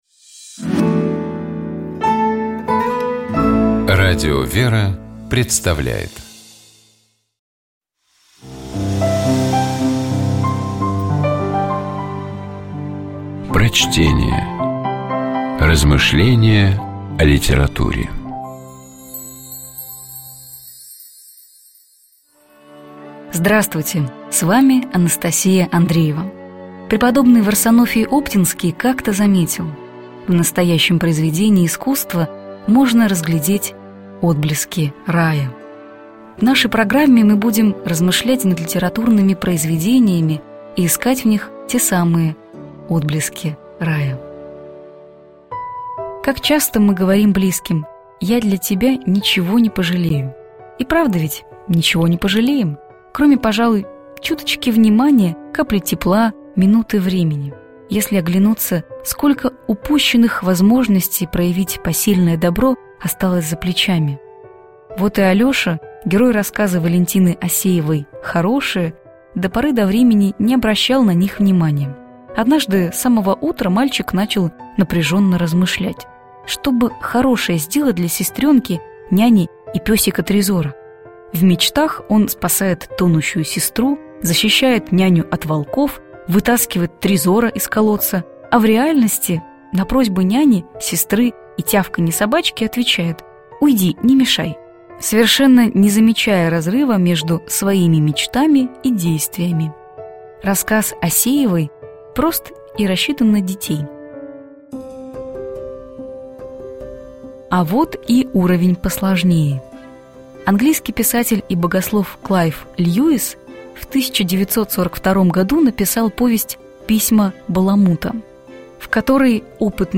Prochtenie-V_-Oseeva-Horoshee-Posilnoe-dobro.mp3